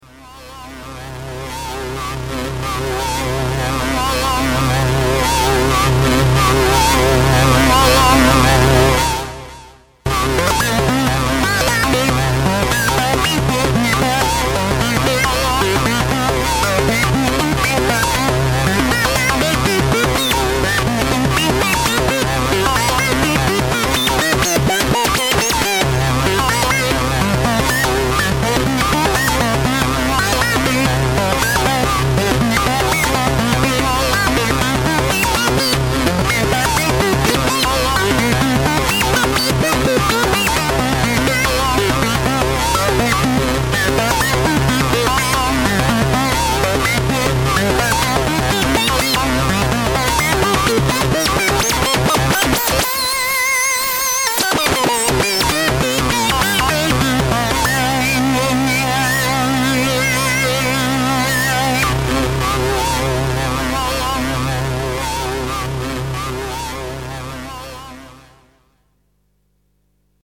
using both lfo and adsr to modulate the main wavetable